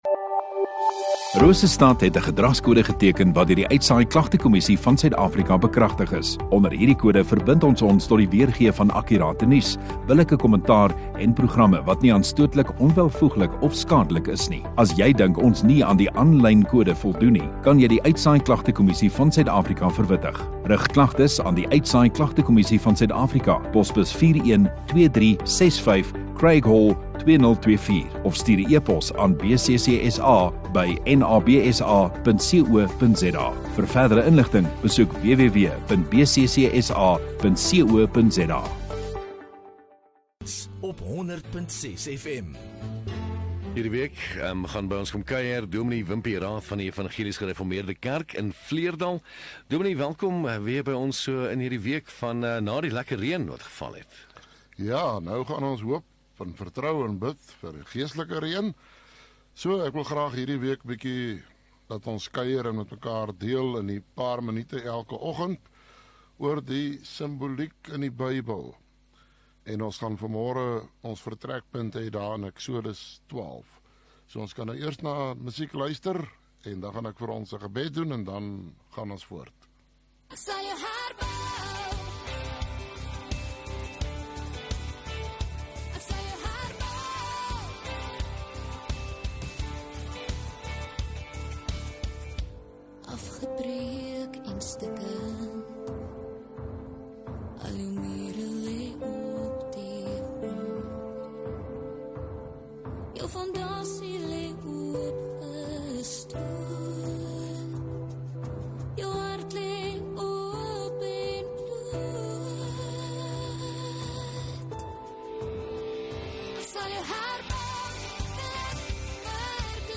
16 Oct Maandag Oggenddiens